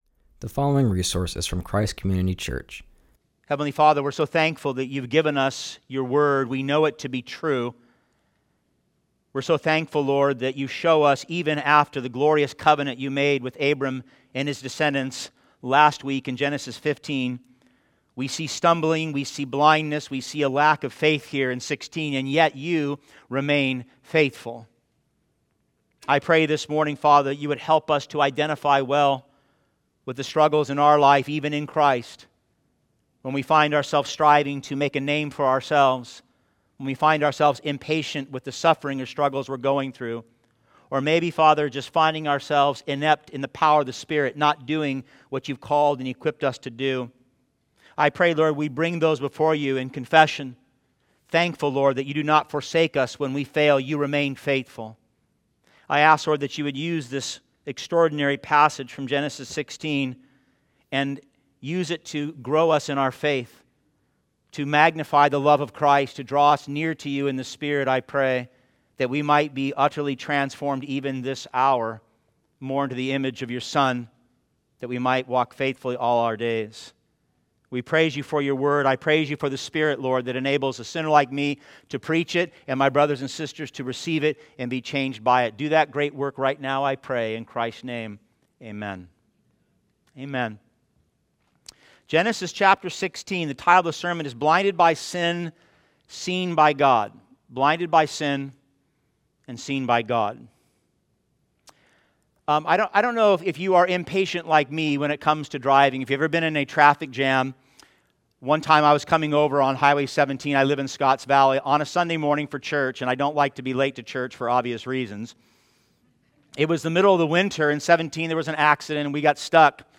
preaches from Genesis 16:1-16.